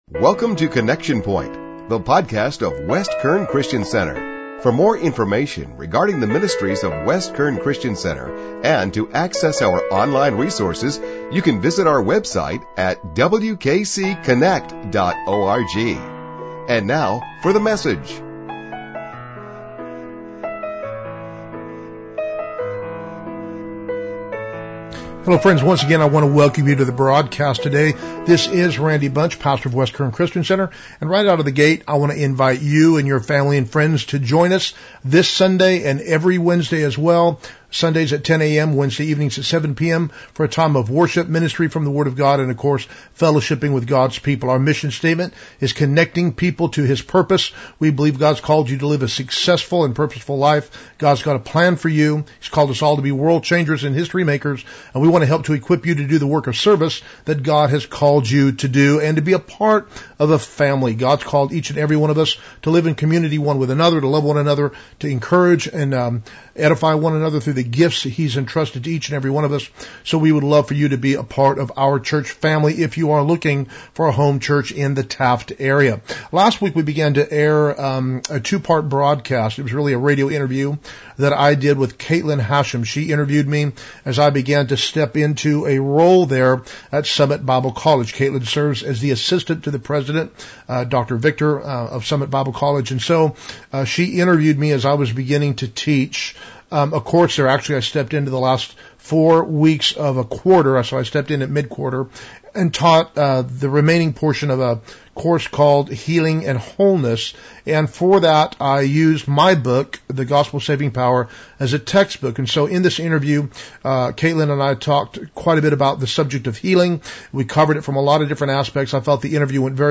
summit-bible-college-radio-interview-part-2.mp3